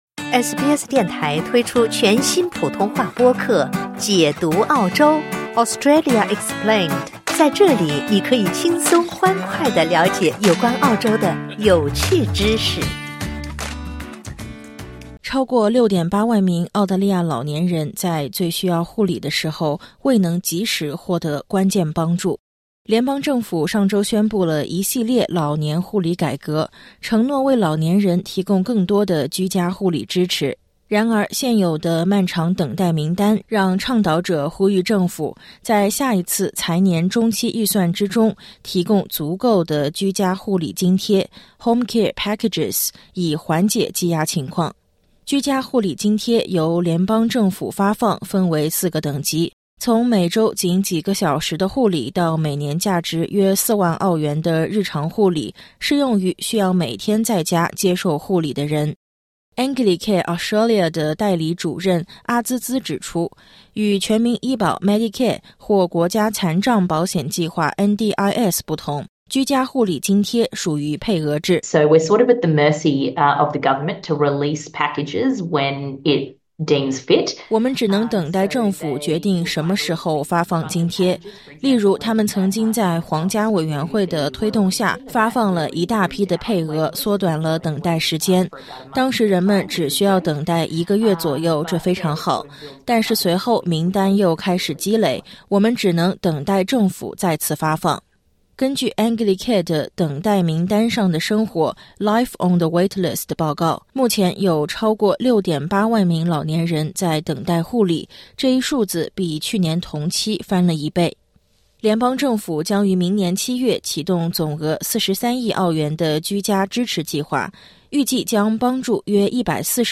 报告显示，数以万计的澳大利亚老年人需要等待长达15个月才能获得居家护理支持。点击音频，收听综合报道。